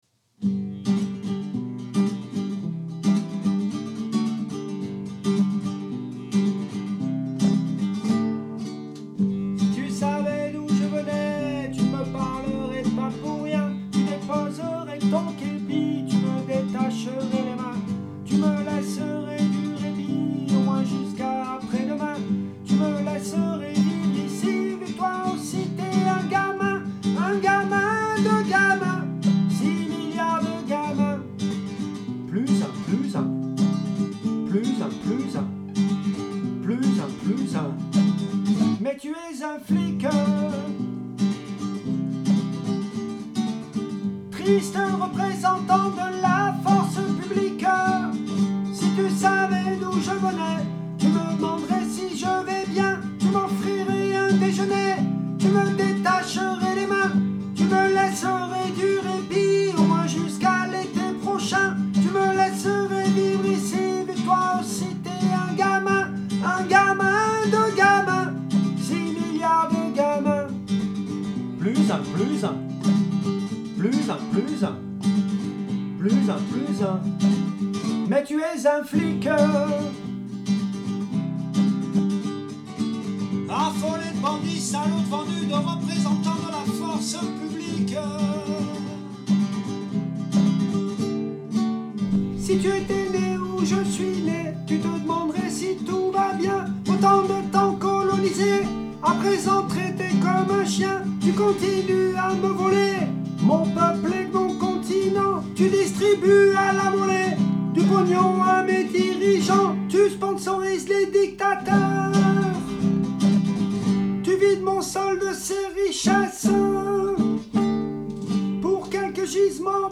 2. Aigue.mp3